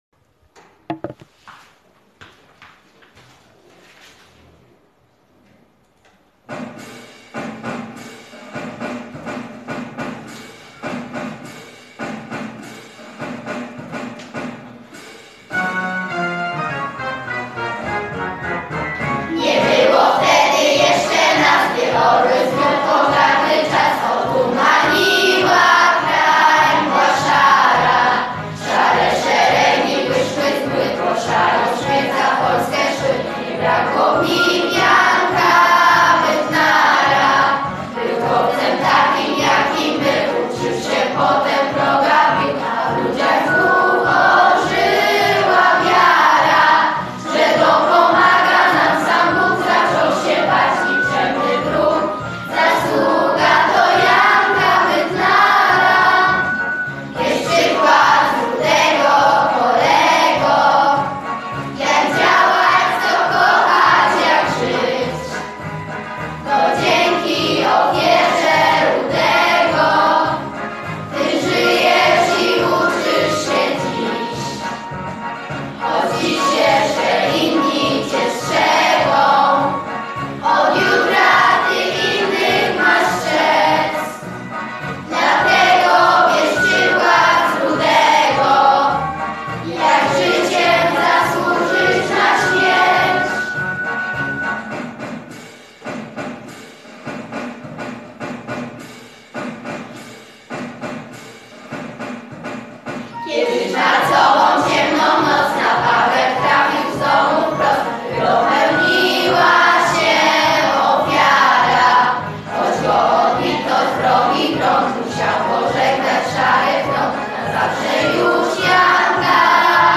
Autorem słów jest Leon Sęk, a muzykę skomponował Wojciech Kacperski.
Hymn w wykonaniu SZKOLNEGO CHÓRU